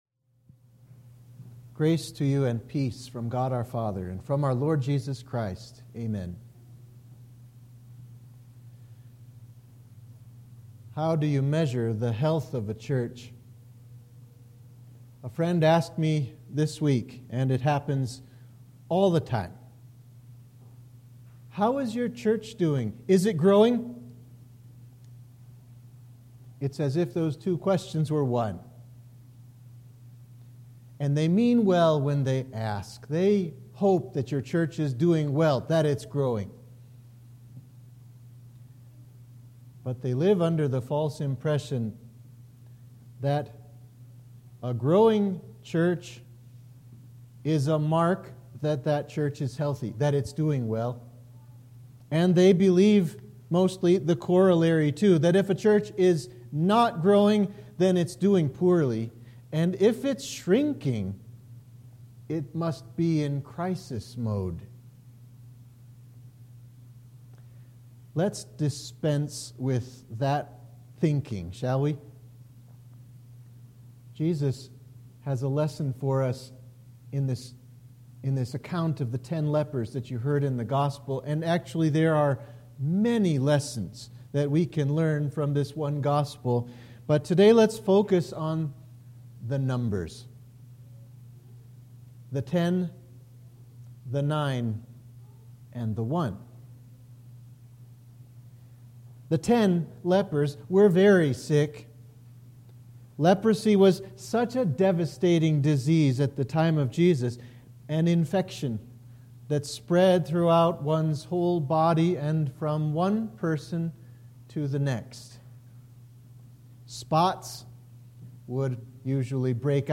Sermon for the Fourteenth Sunday after Trinity